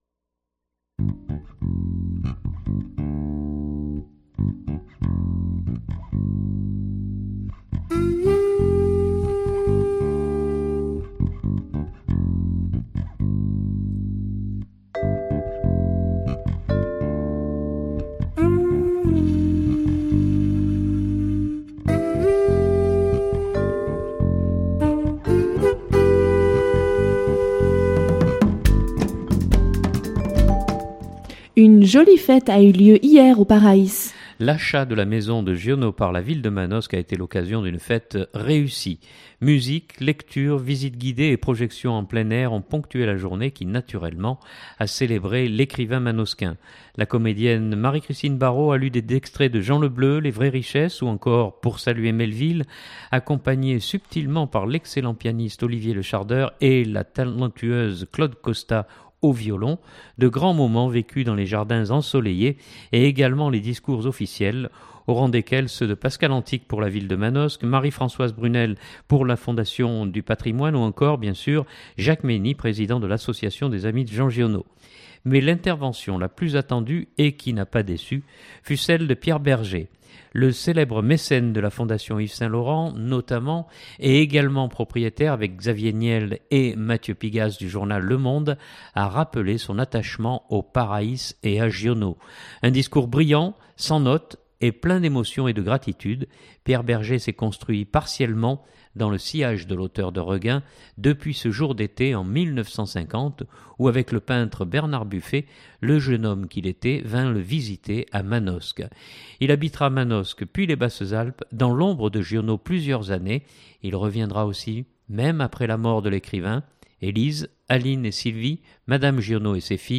Le célèbre mécène de la Fondation Yves Saint-Laurent notamment, et également propriétaire avec Xavier Niel et Mathieu Pigasse du journal « Le Monde » a rappelé son attachement au Paraïs et à Giono. Un discours brillant, sans notes, et plein d’émotion et de gratitude.